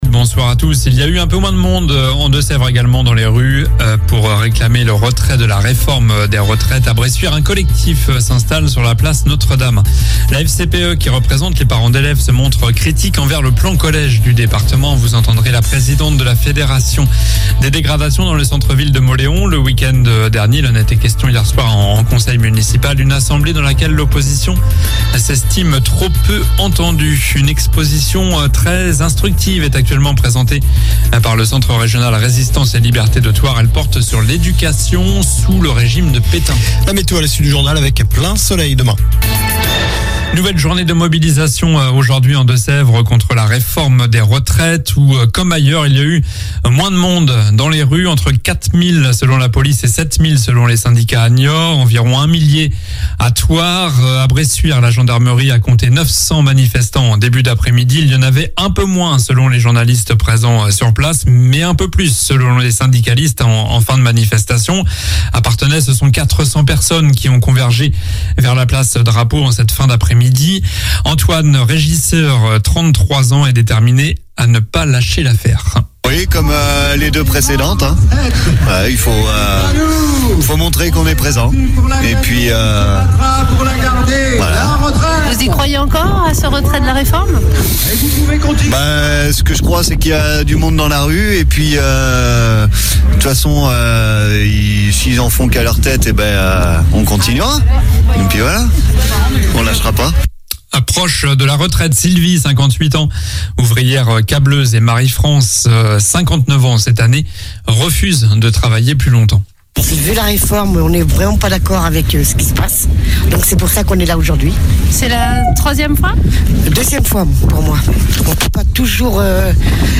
Journal du mardi 7 février (soir)